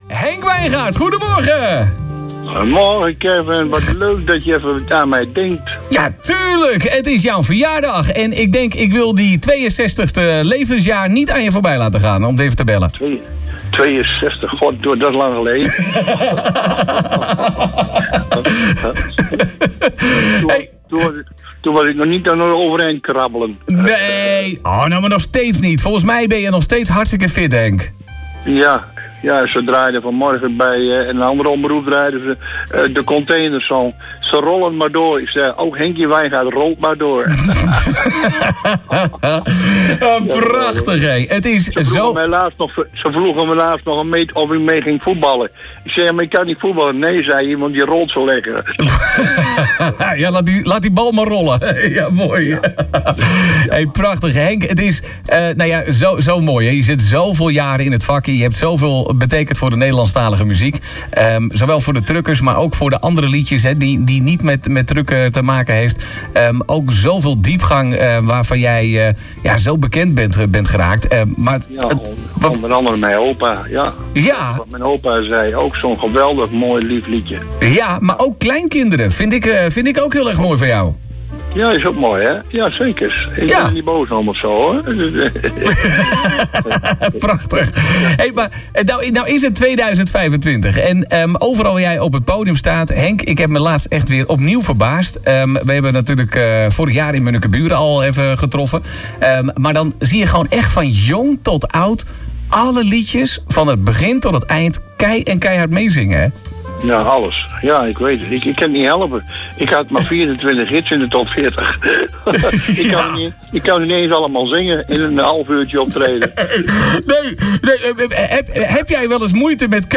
BELLEN: JARIGE HENK WIJNGAARD (AUDIO)
🎉 Wij belden met de jarige Henk Wijngaard! 🎉